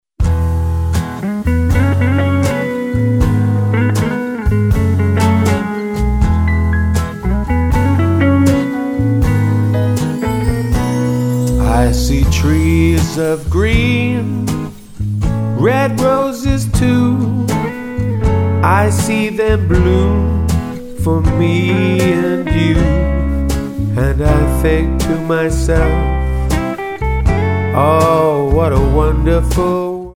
Tonart:E Multifile (kein Sofortdownload.
Die besten Playbacks Instrumentals und Karaoke Versionen .